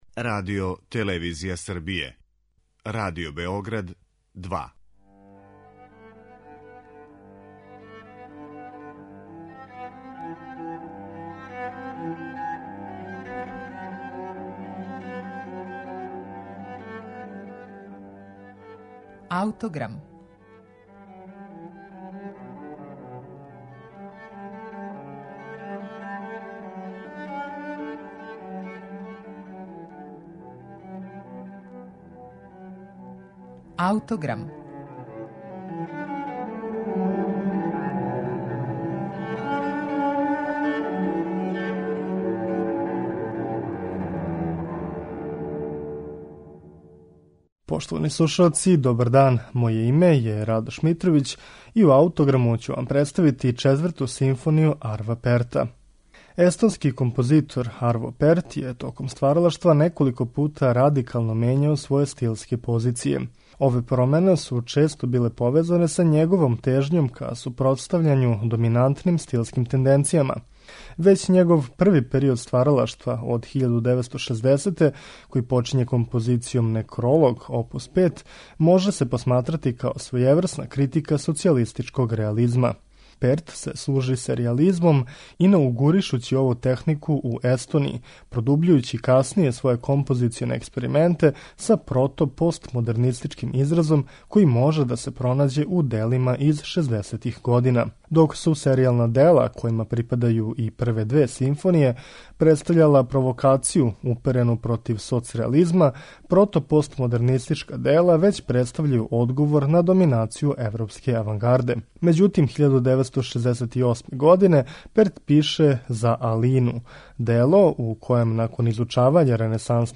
симфонији